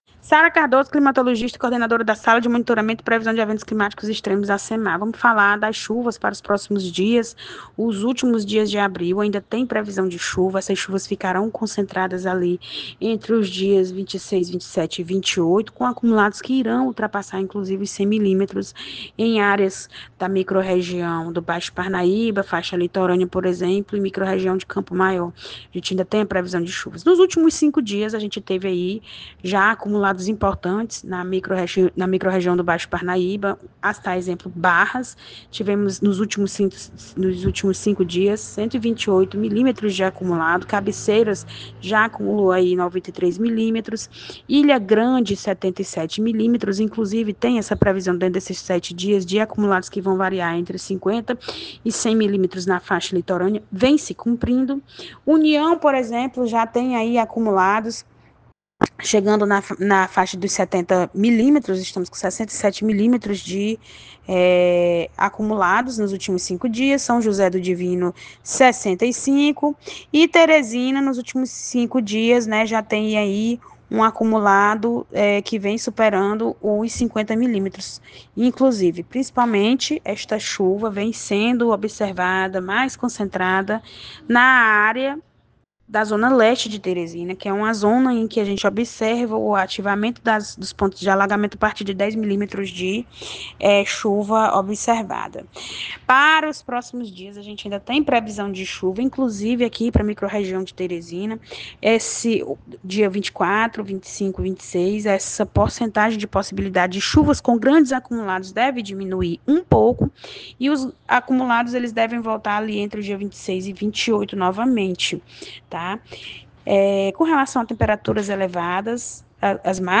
Ouça a climatologista: